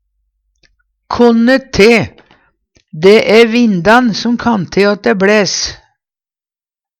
konne te - Numedalsmål (en-US)